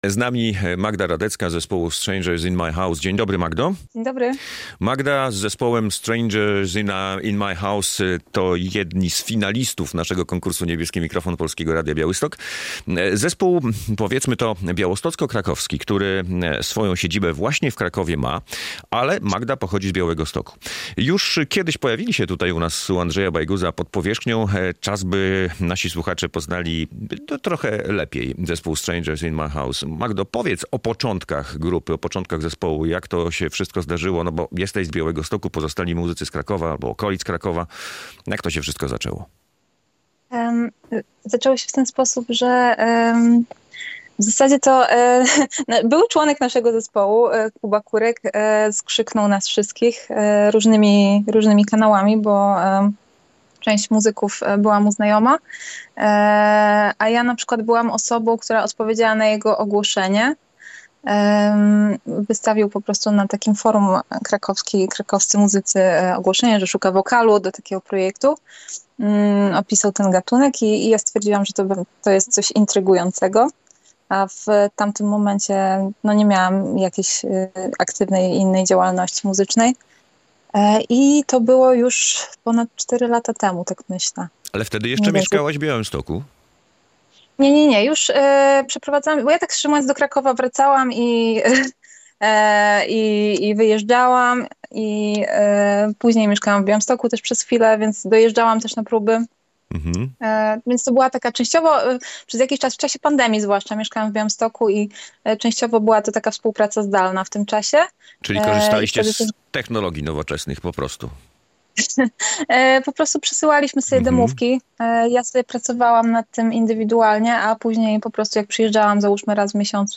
Radio Białystok | Gość